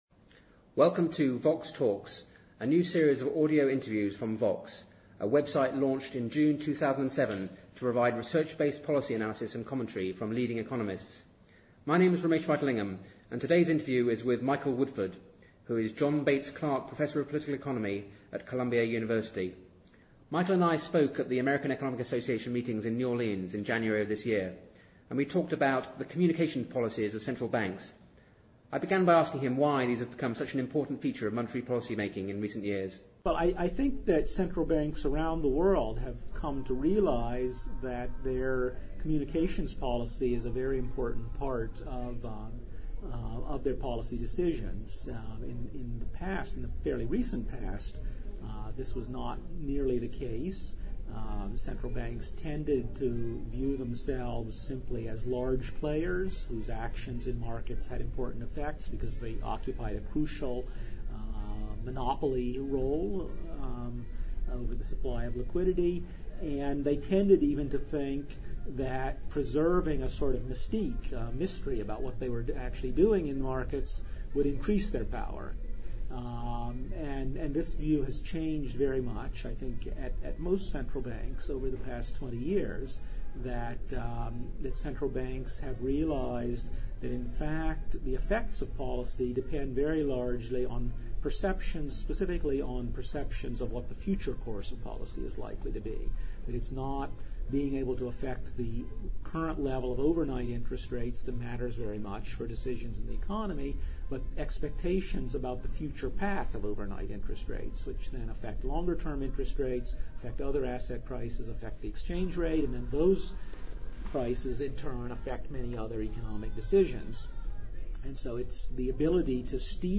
In an interview recorded at the American Economic Association meetings in New Orleans in January 2008